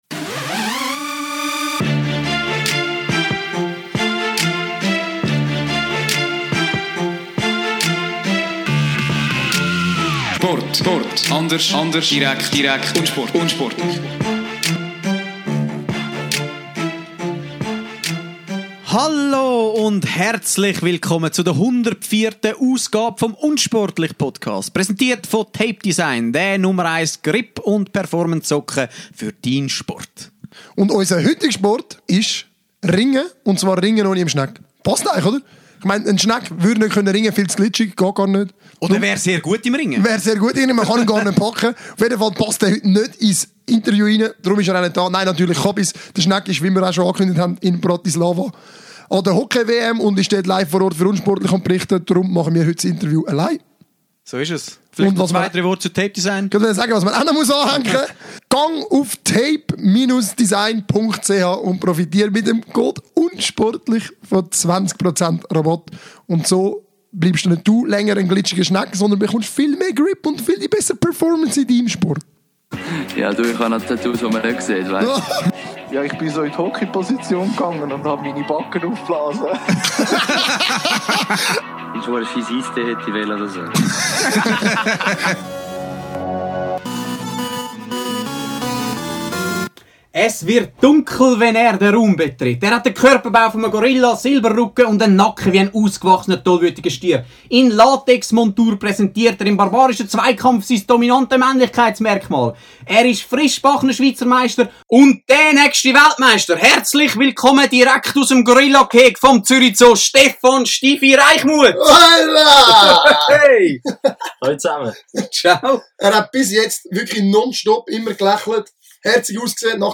weshalb er immer noch in Bratislava festsitzt! xD PS: Entschuldigt die Tonqualität.